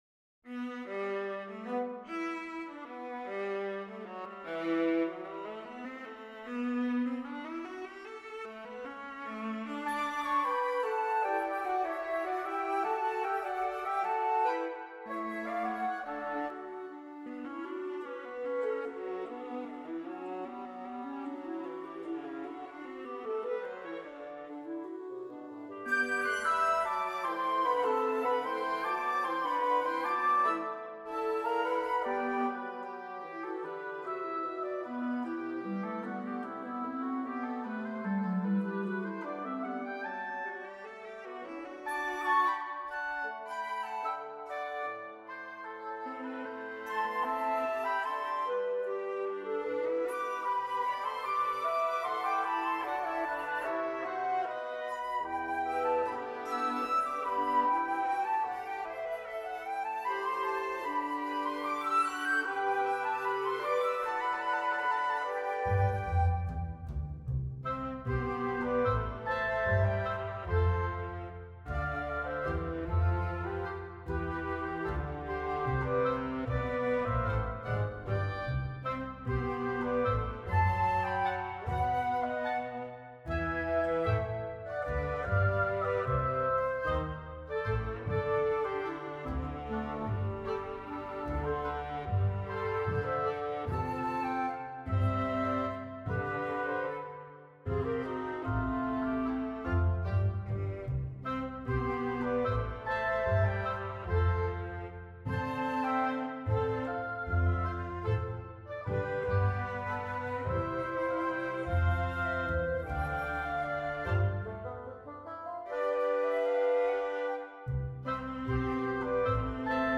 I'm well over 2 minutes and it's already 150bpm!